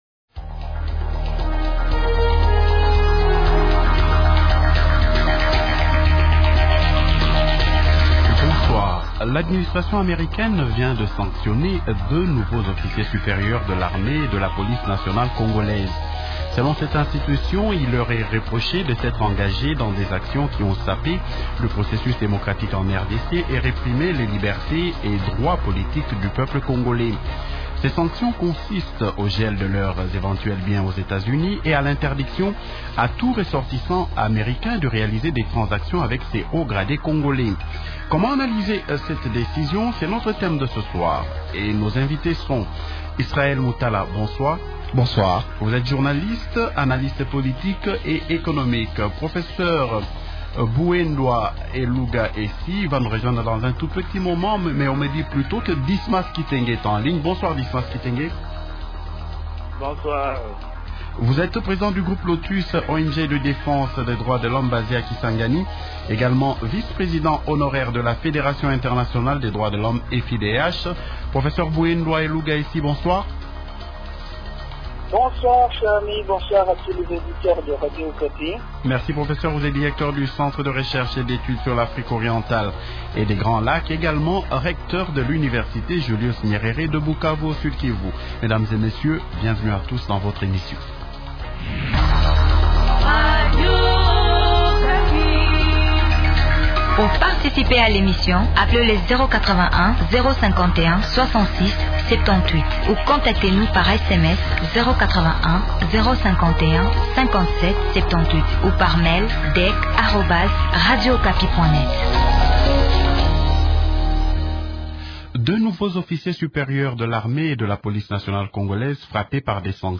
Les analystes de ce soir sont :